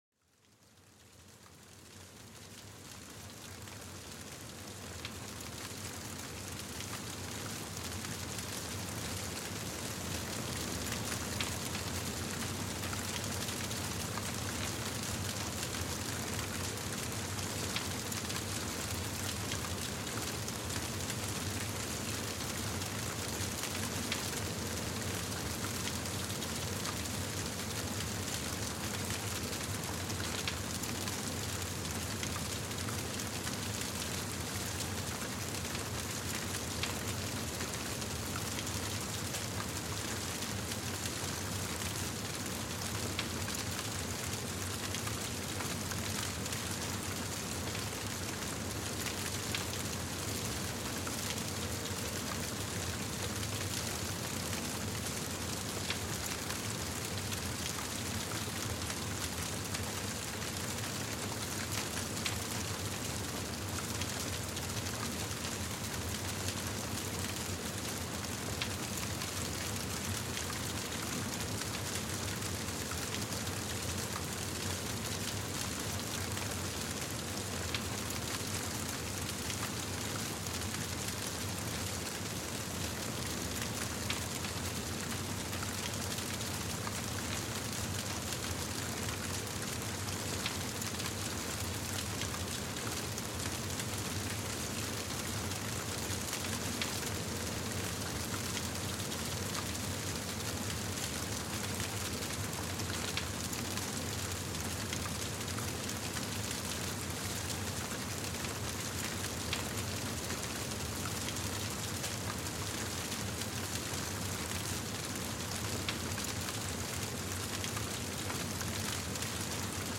Le crépitement apaisant du feu pour calmer l'esprit